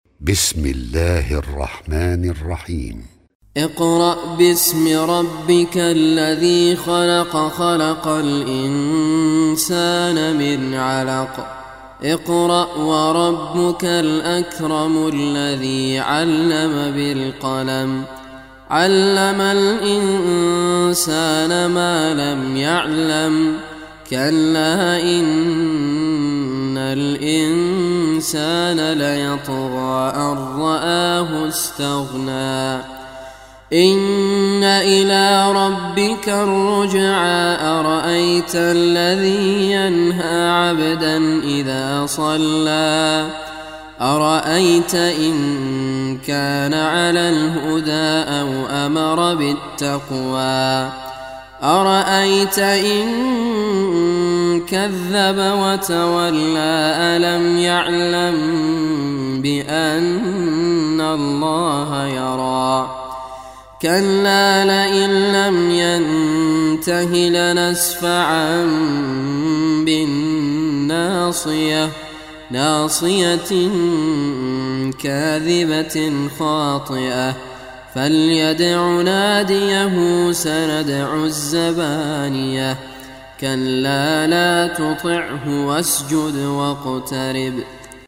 Surah Al-Alaq Recitation by Sheikh Raad Al Kurdi
Surah Al-Alaq, listen or play online mp3 tilawat / recitation in Arabic in the beautiful voice of Sheikh Raad Al Kurdi.